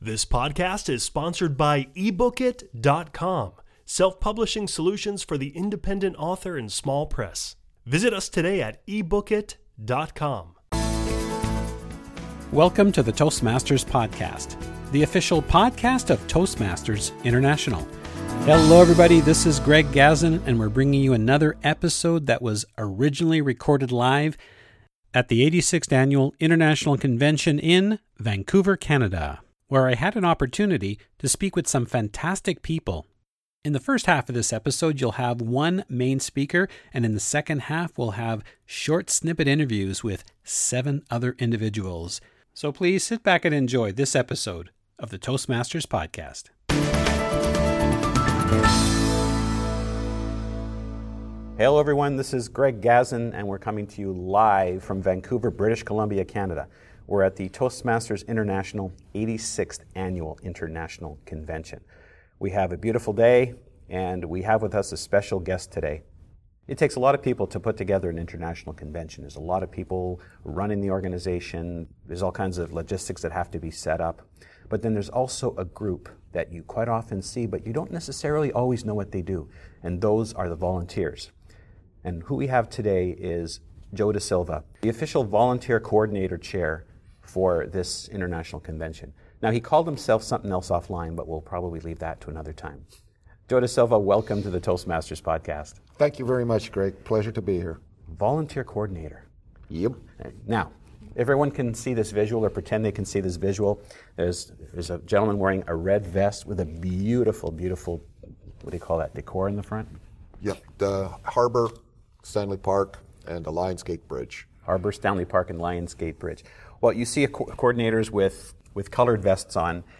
Interviews with 2017 International Convention volunteers who share their experiences.